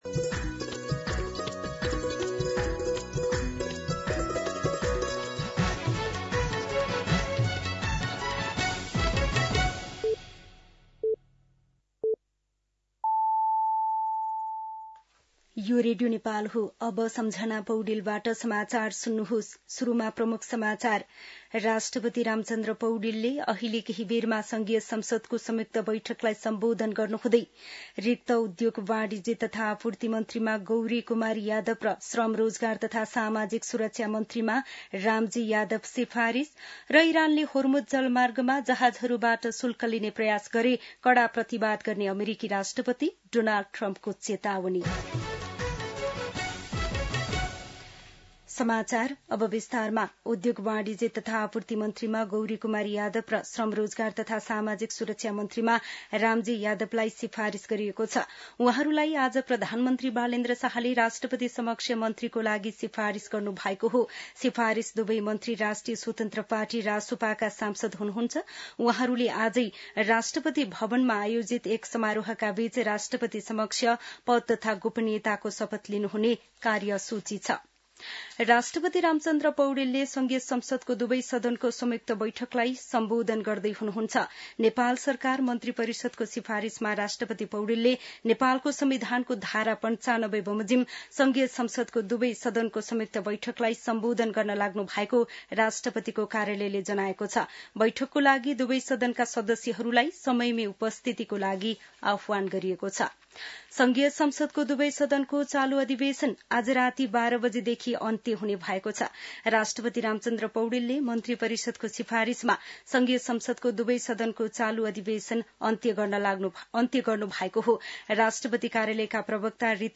दिउँसो ३ बजेको नेपाली समाचार : २७ चैत , २०८२
3-pm-News-12-27.mp3